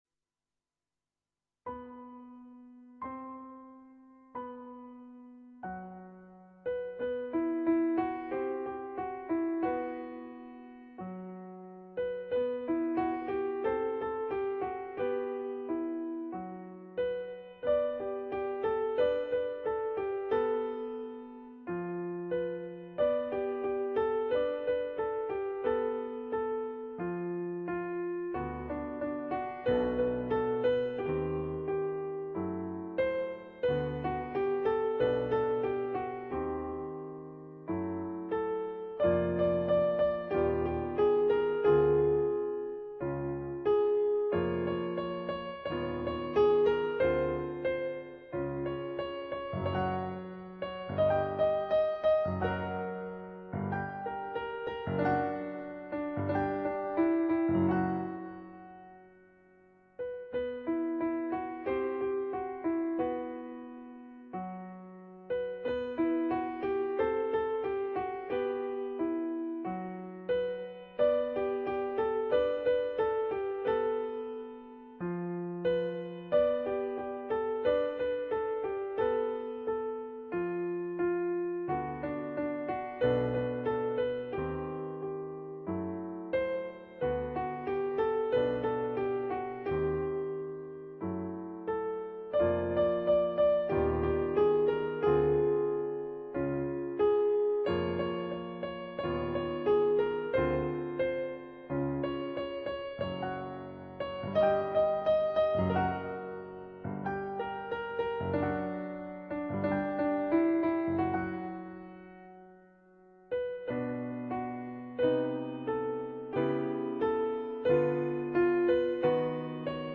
adapted for Two Pianos
on Yamaha digital pianos.